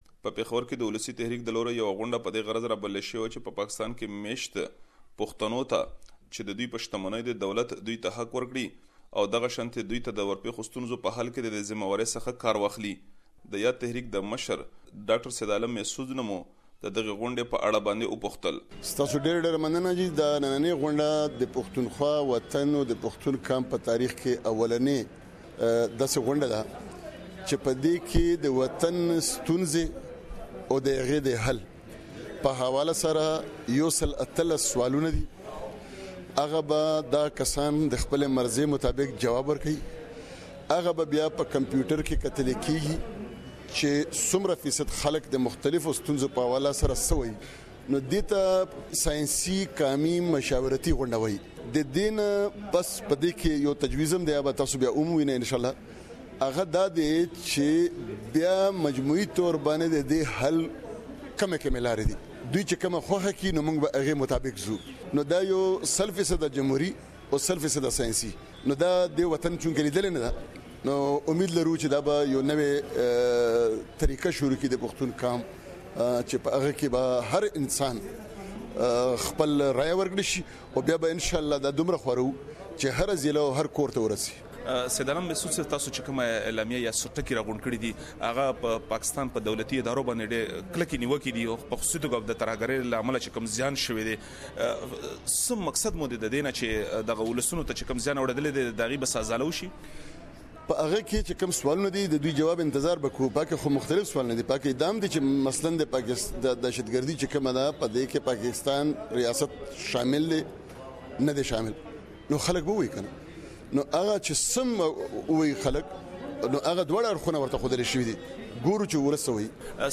Pakhtunkhwa Ulasi Tehrik held a historic meeting